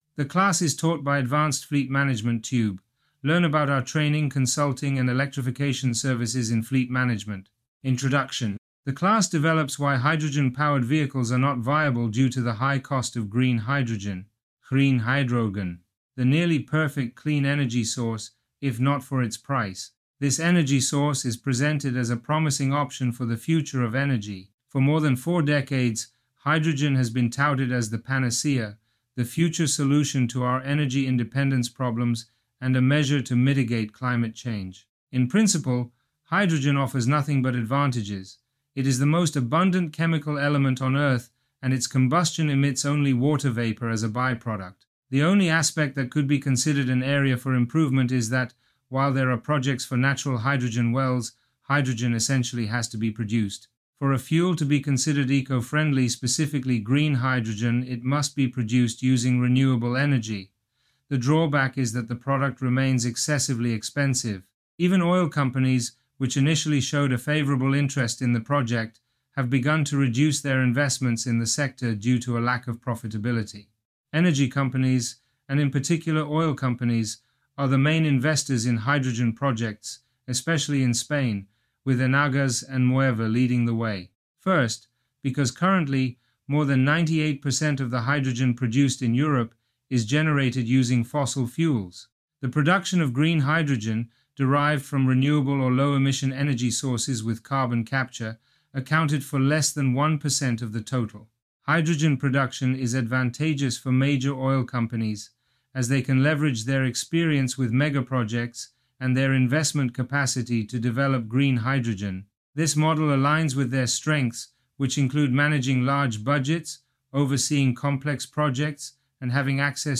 Online class